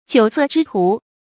酒色之徒 注音： ㄐㄧㄨˇ ㄙㄜˋ ㄓㄧ ㄊㄨˊ 讀音讀法： 意思解釋： 嗜酒好色的人。